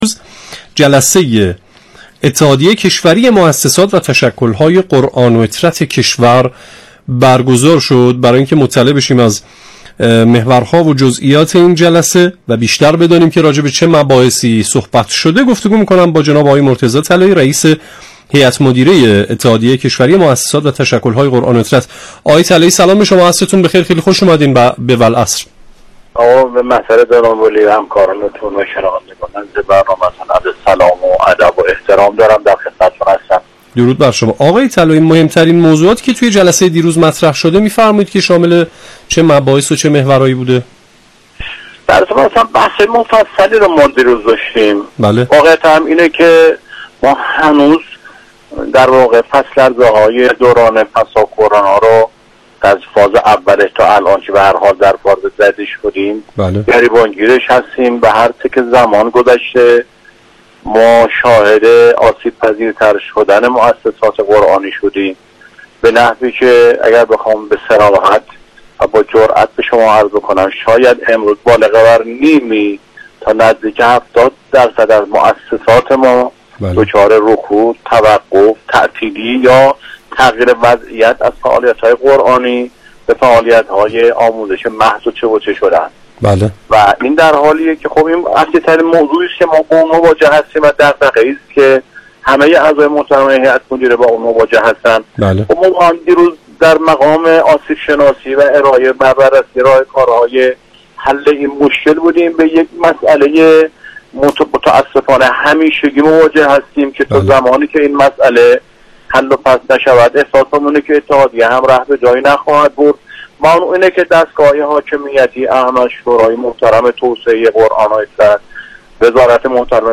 یادآور می‌شود، این گفت‌وگو در برنامه والعصر رادیو قرآن انجام شد.